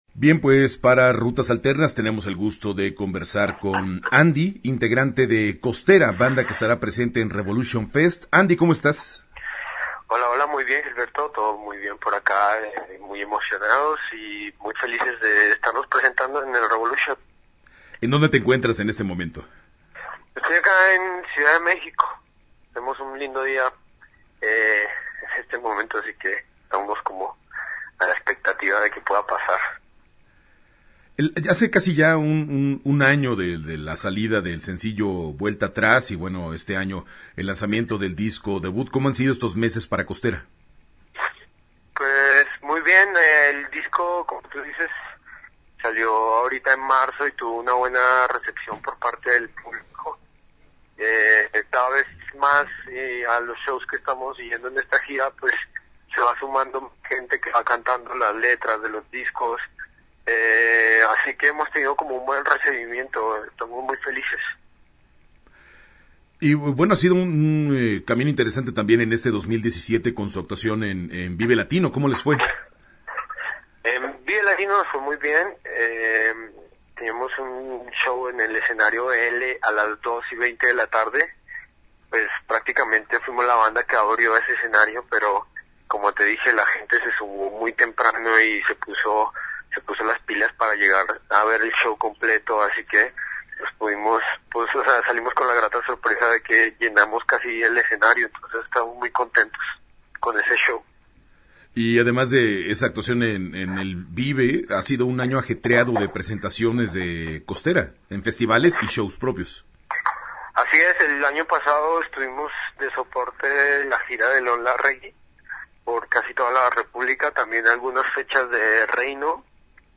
Entrevista-Costera-web.mp3